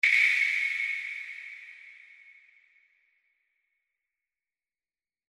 GOTA DE AGUA GOTA DE AGUA
Ambient sound effects
GOTA_DE_AGUA_gota_de_agua.mp3